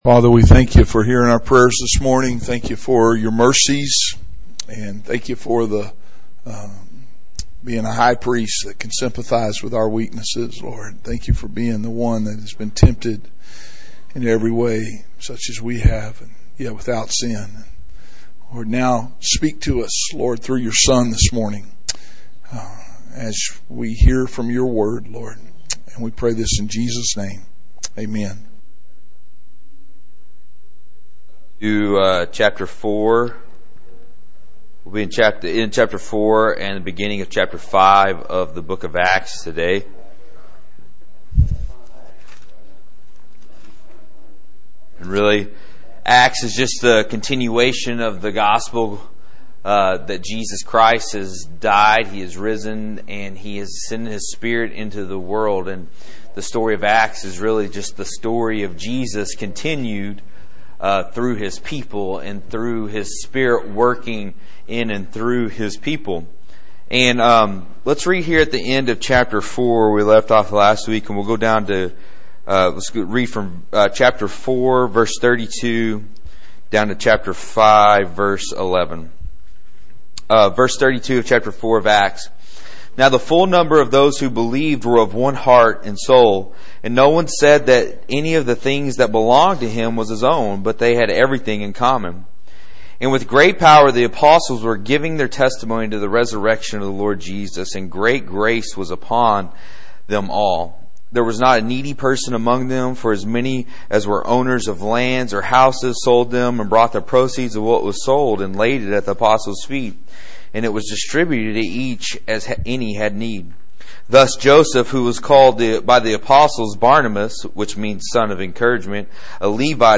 5/17/15 – Adult Bible Study